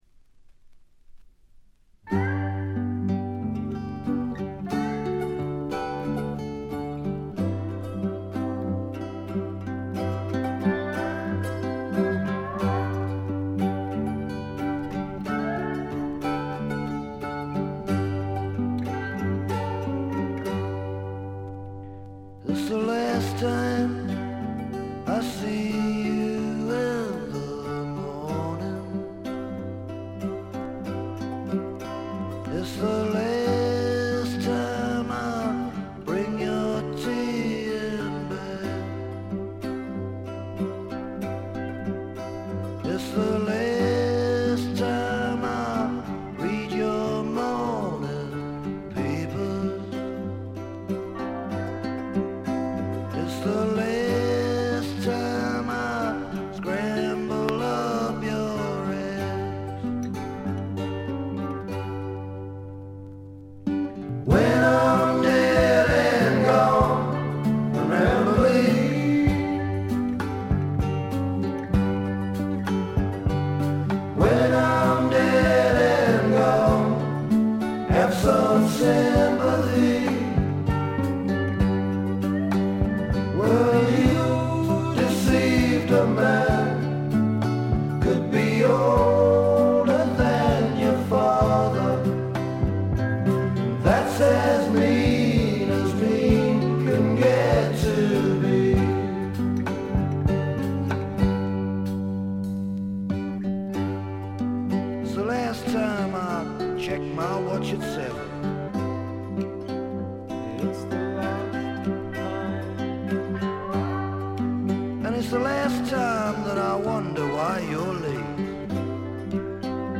軽微なチリプチ少々、プツ音少々。
試聴曲は現品からの取り込み音源です。
Recorded At - Riverside Studios, London